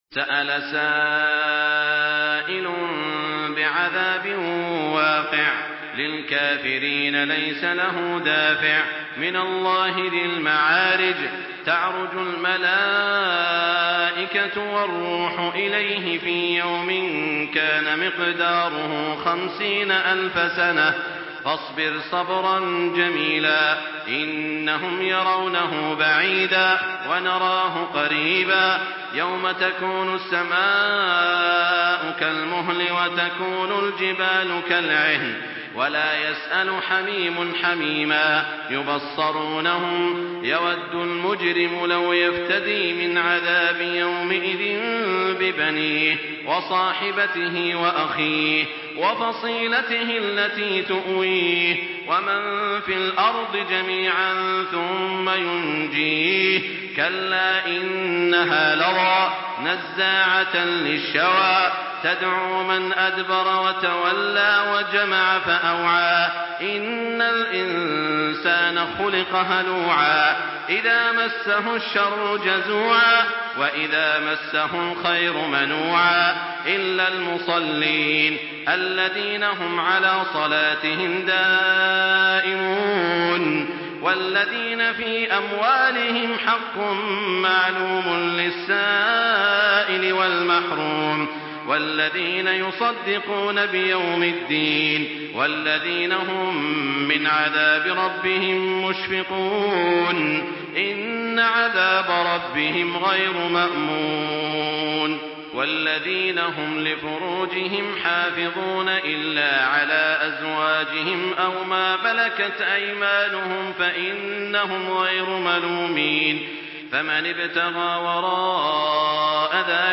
سورة المعارج MP3 بصوت تراويح الحرم المكي 1424 برواية حفص عن عاصم، استمع وحمّل التلاوة كاملة بصيغة MP3 عبر روابط مباشرة وسريعة على الجوال، مع إمكانية التحميل بجودات متعددة.
تحميل سورة المعارج بصوت تراويح الحرم المكي 1424